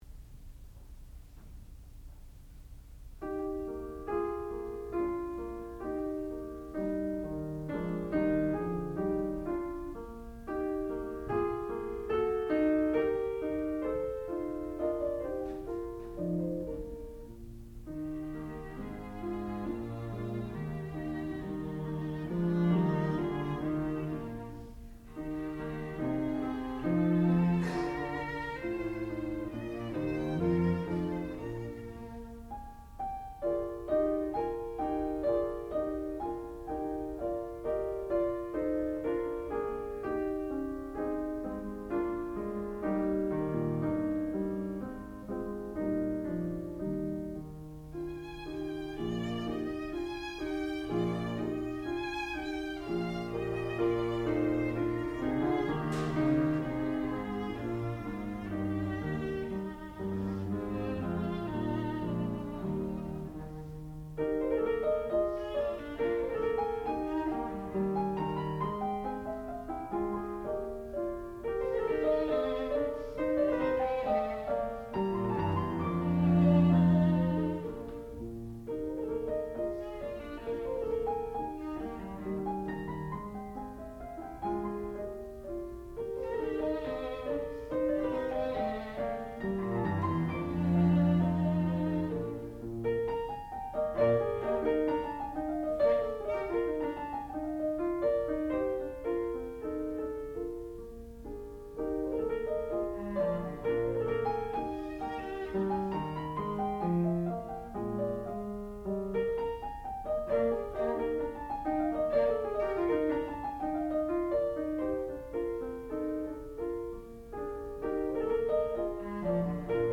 sound recording-musical
classical music
Master's Degree Recital